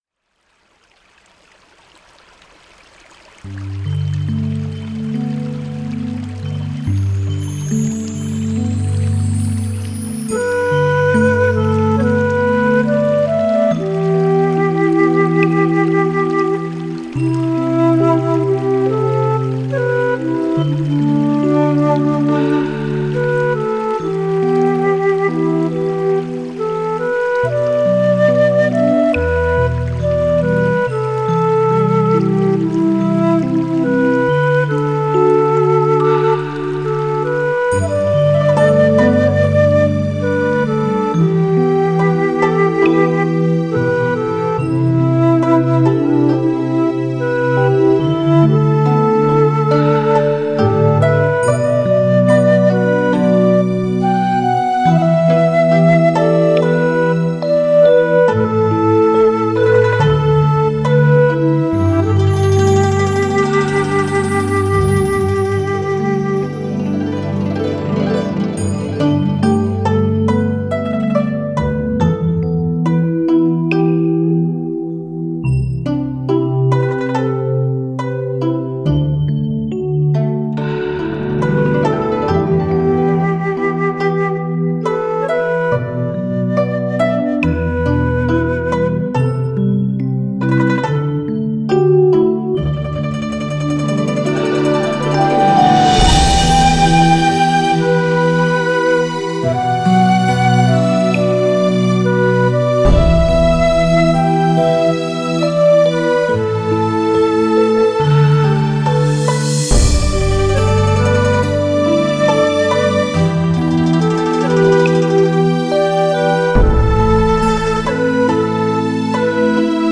悠扬低婉的江南丝竹